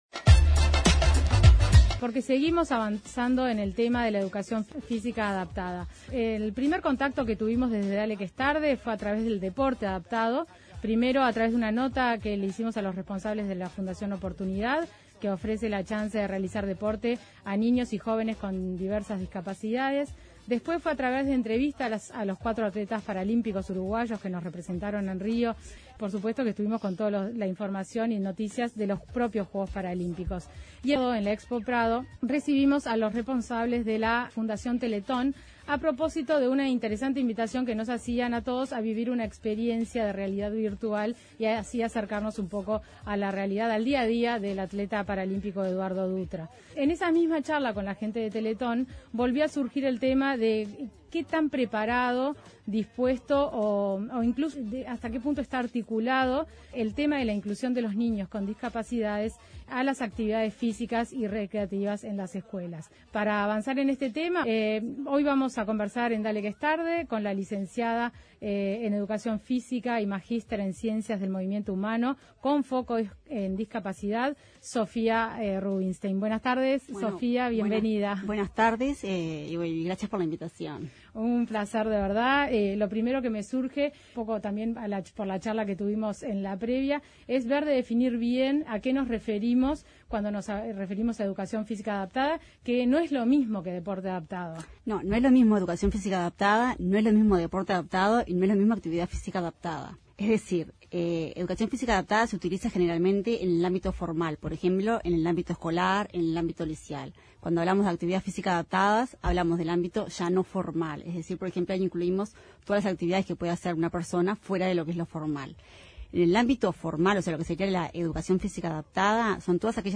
Descargar Audio no soportado En los martes de deporte y sociedad recibimos